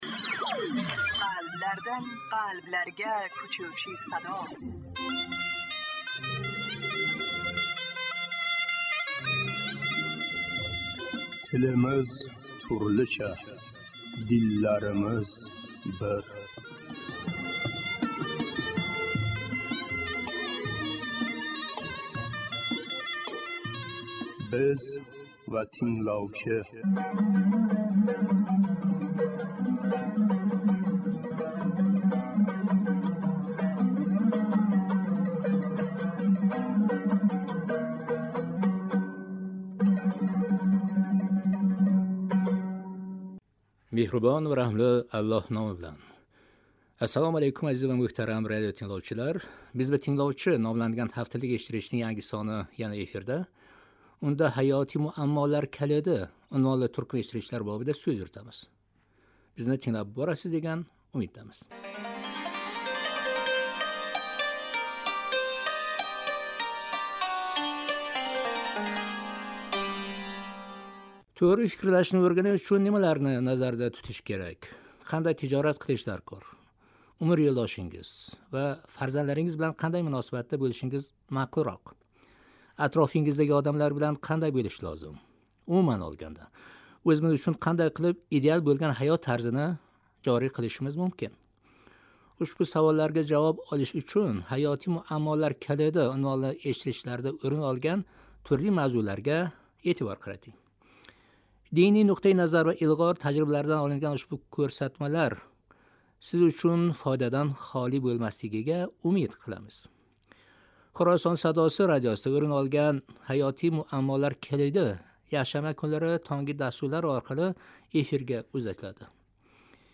"Биз ва тингловчи" номланган ҳафталик эшиттиришнинг янги сони яна эфирда.